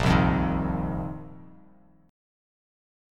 A#sus2sus4 chord